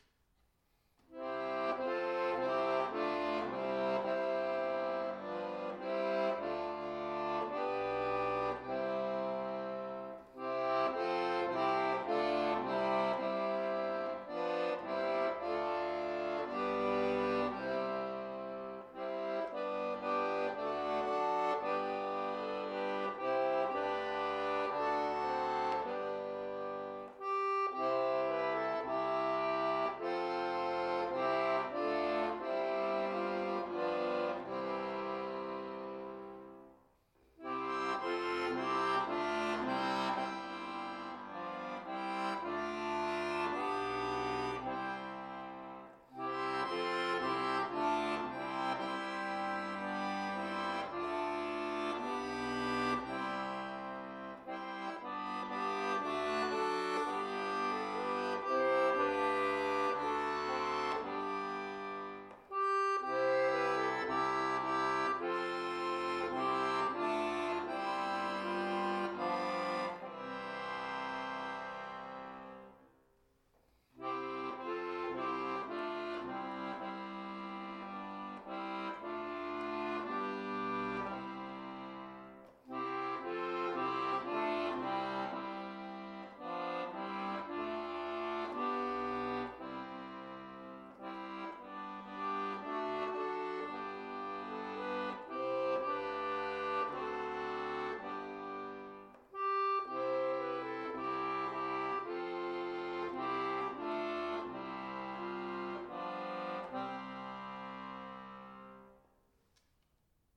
Beispiel für möglichen Akkordeoneinsatz bei Trauerfeiern
So_nimm_denn_meine_Haende_Akkordeon1.mp3